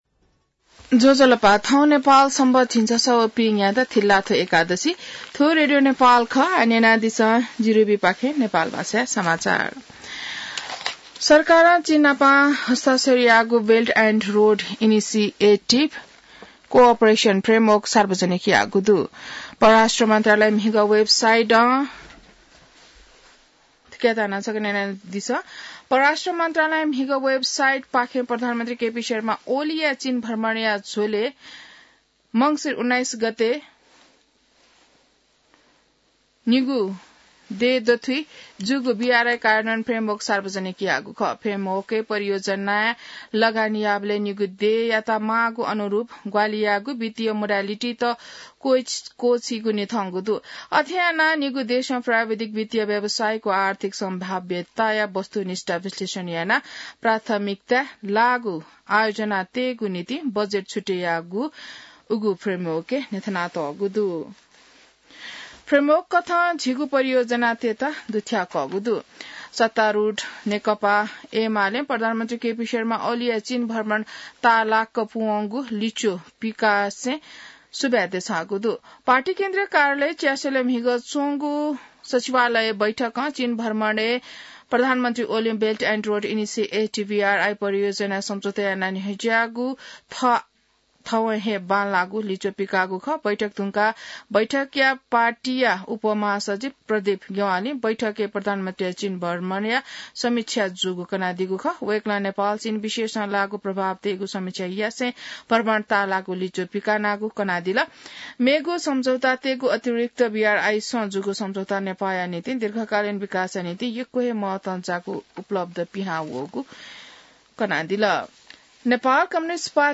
नेपाल भाषामा समाचार : २७ मंसिर , २०८१